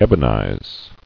[eb·on·ize]